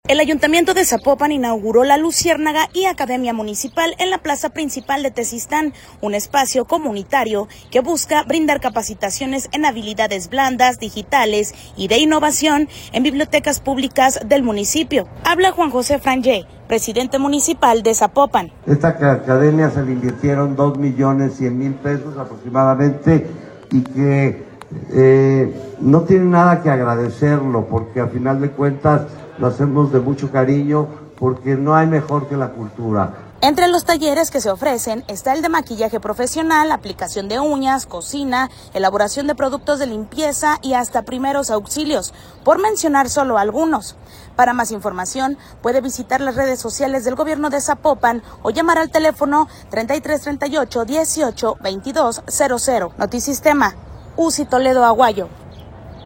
Habla Juan José Frangie, presidente municipal de Zapopan.”